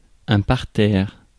Ääntäminen
Tuntematon aksentti: IPA: /paʁ.tɛʁ/